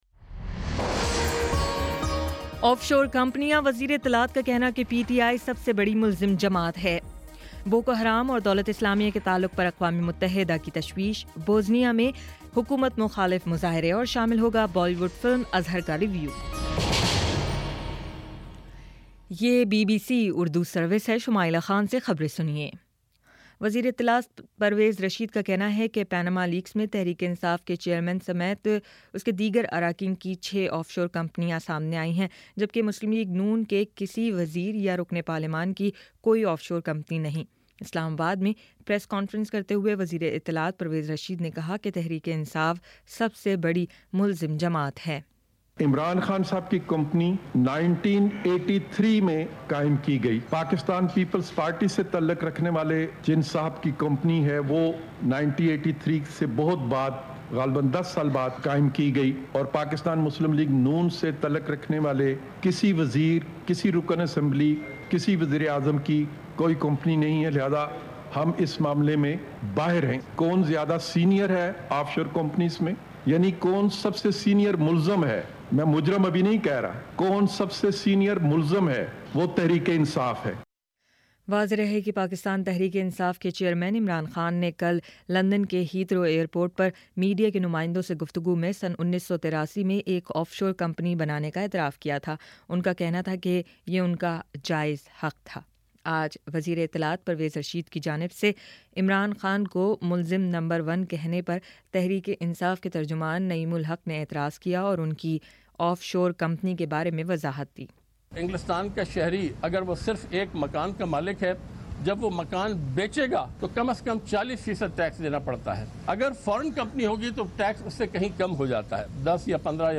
مئی 14 : شام چھ بجے کا نیوز بُلیٹن